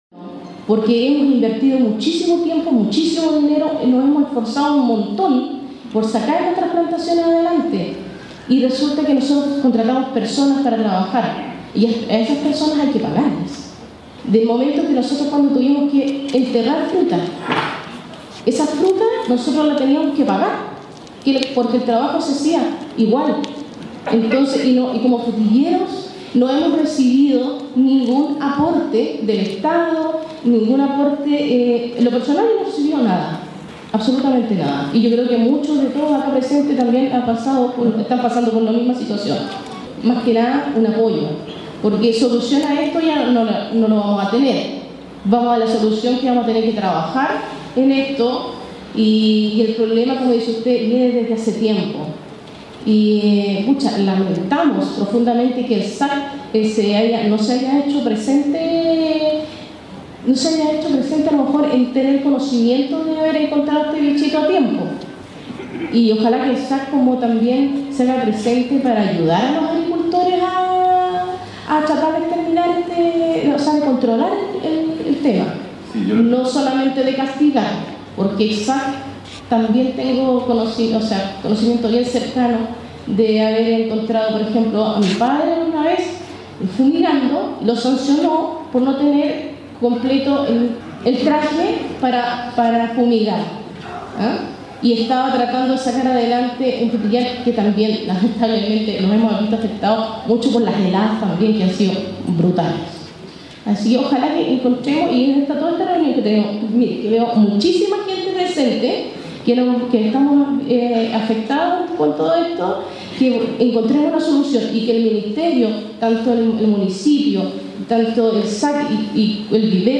El encuentro vivió momentos tensos tras dar inicio a la ronda de preguntas en donde vecinos comenzaron a realizar sus descargos, fue así como una de las vecinas señalo que “hemos invertido muchísimo tiempo, muchísimo dinero, nos hemos esforzado un montón por sacar nuestras plantaciones adelante” sentencio la productora sanpedrina -de quien no tenemos el nombre- y que fue aplaudida por los asistentes.
vecina-audio-ambiente-Sin-Nombre.mp3